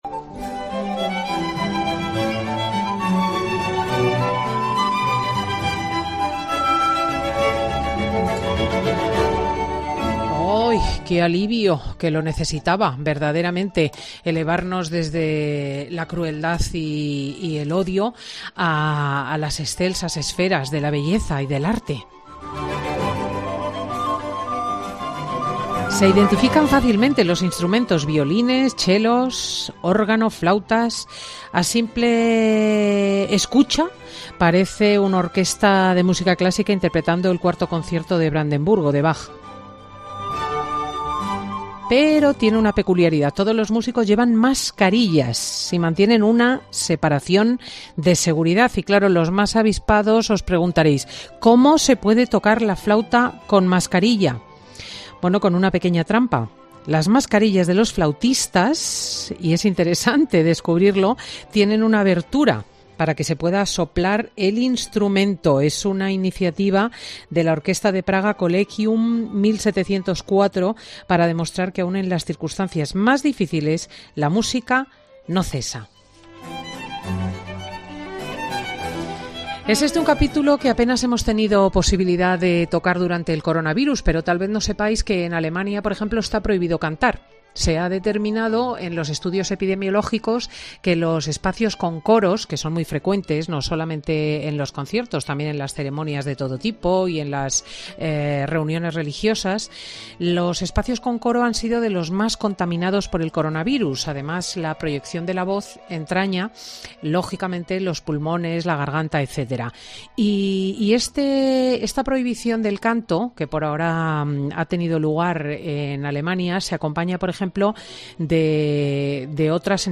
Dos músicos de la Escuela Superior de Música Reina Sofía nos comentan cómo se preparan para volver a la normalidad